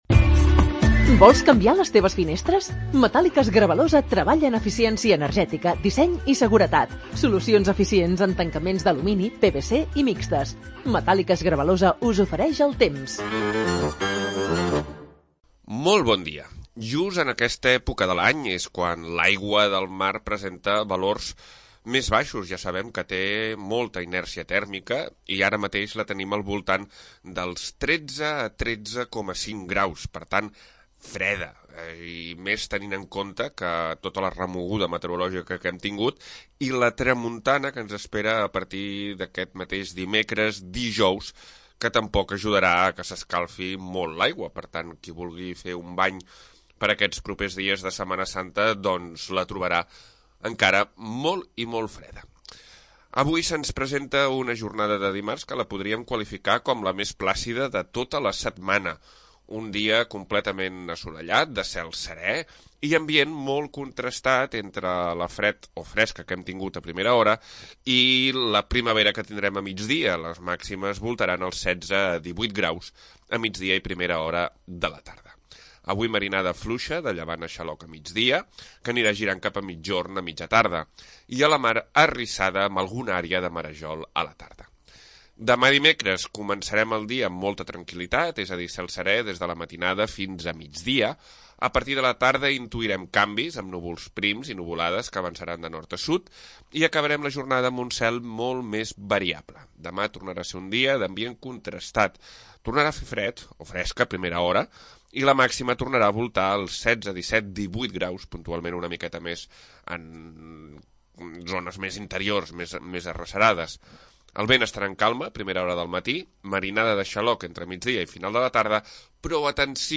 Previsió meteorològica 24 de març de 2026